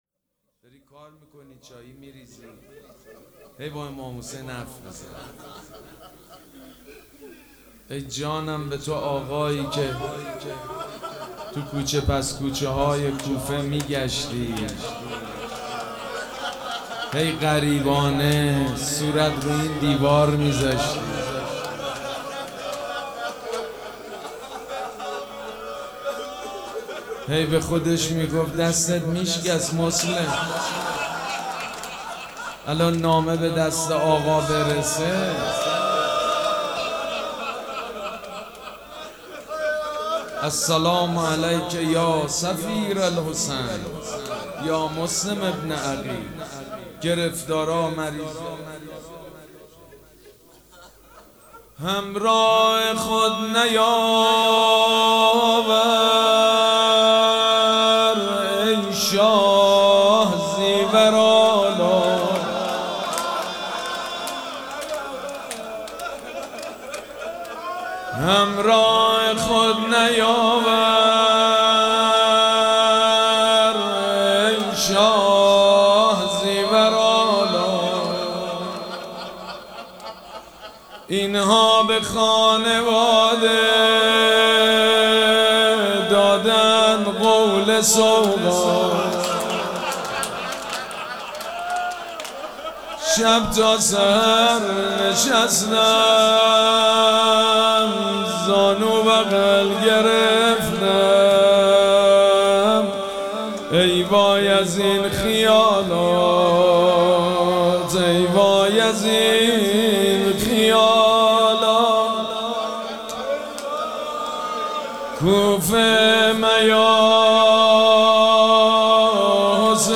مراسم عزاداری شهادت امام محمد باقر و حضرت مسلم سلام‌الله‌علیهما
روضه
حاج سید مجید بنی فاطمه